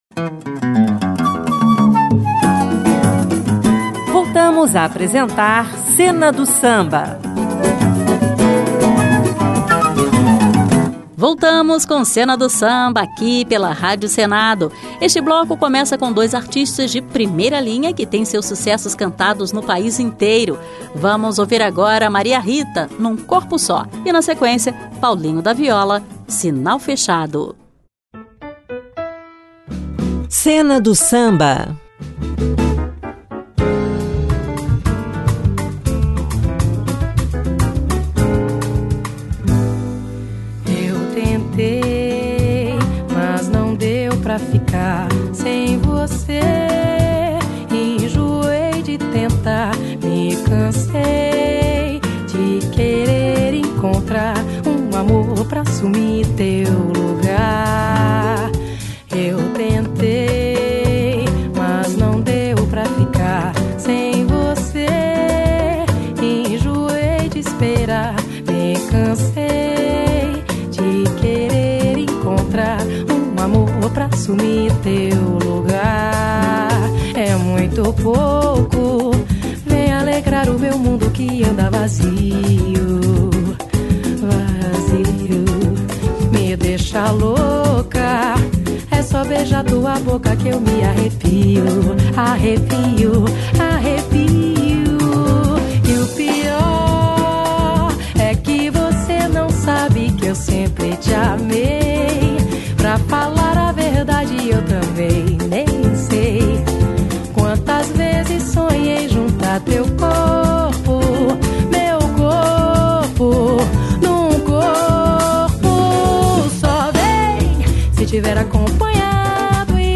uma seleção musical repleta de sucessos